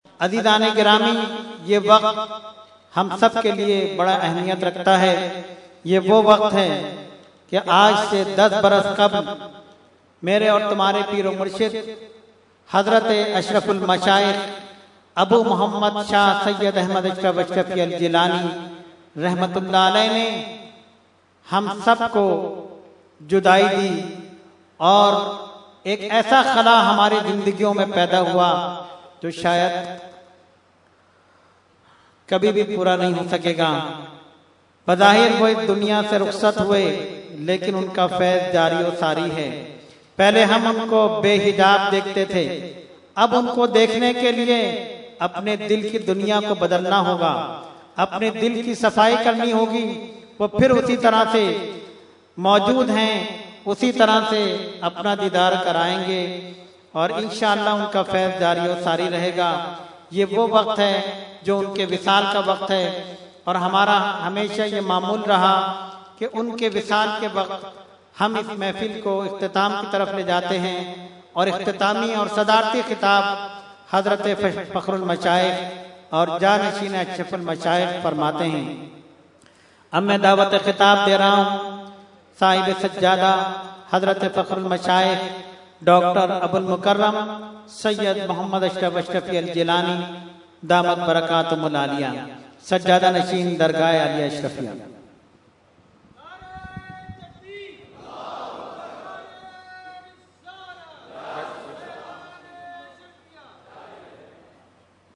Category : Speech | Language : UrduEvent : Urs Ashraful Mashaikh 2015